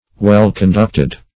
well-conducted - definition of well-conducted - synonyms, pronunciation, spelling from Free Dictionary
well-conducted.mp3